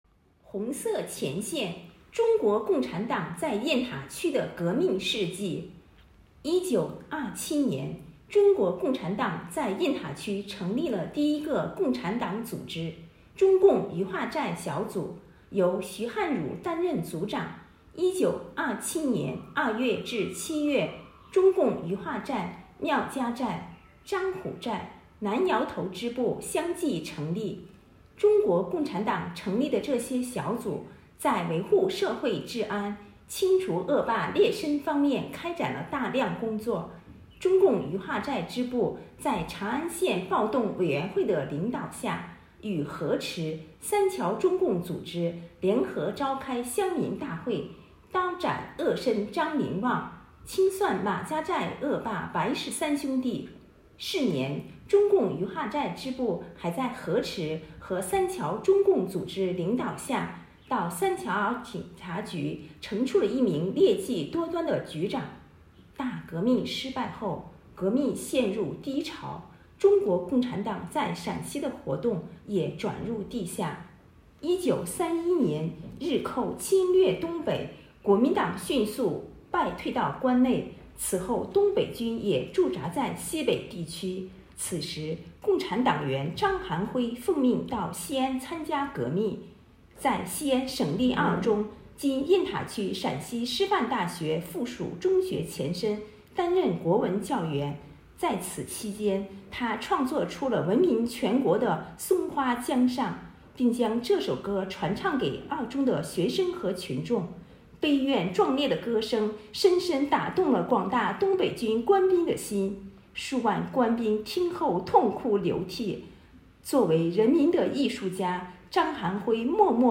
【红色档案诵读展播】中国共产党在雁塔区的革命事迹